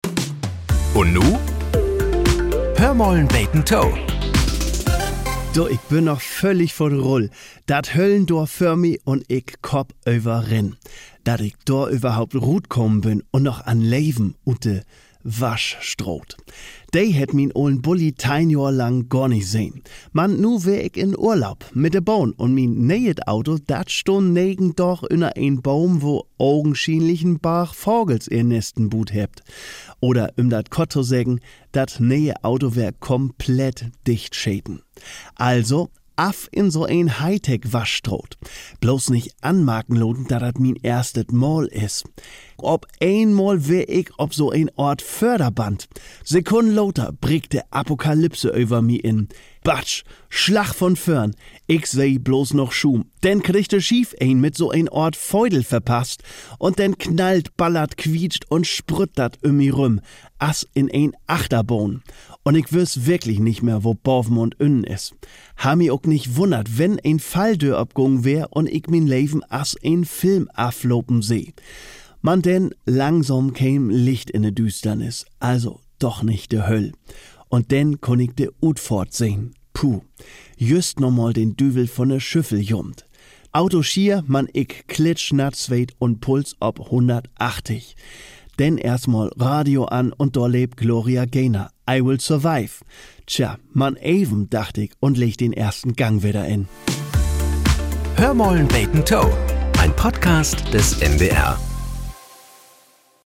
Nachrichten - 25.05.2025